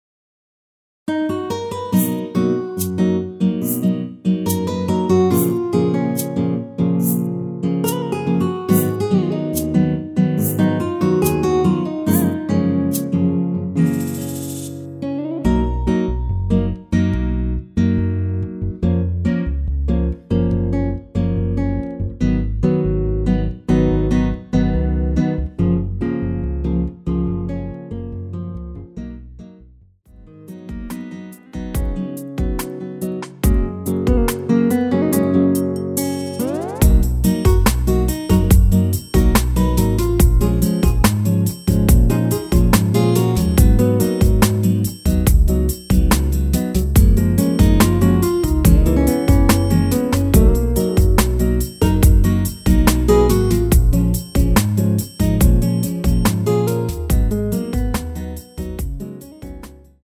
원키에서(-3)내린 MR입니다.
F#
앞부분30초, 뒷부분30초씩 편집해서 올려 드리고 있습니다.
중간에 음이 끈어지고 다시 나오는 이유는